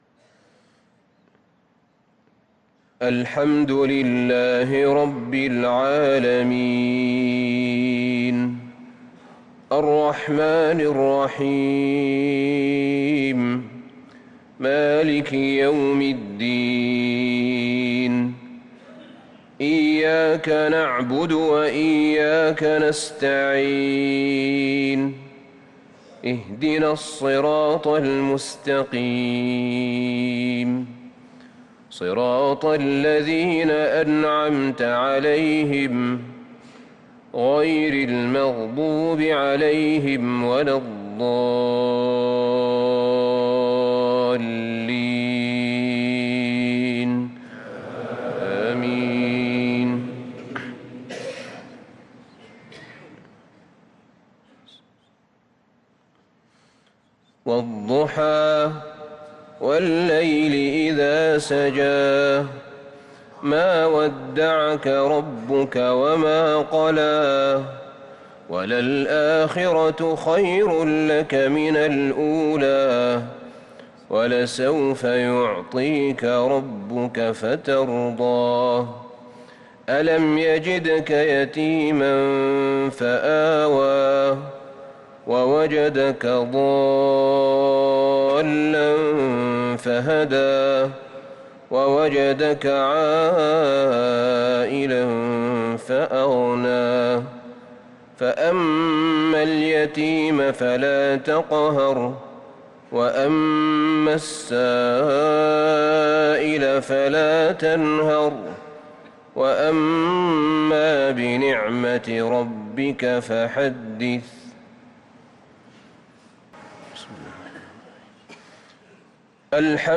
صلاة المغرب للقارئ أحمد بن طالب حميد 18 ربيع الآخر 1444 هـ
تِلَاوَات الْحَرَمَيْن .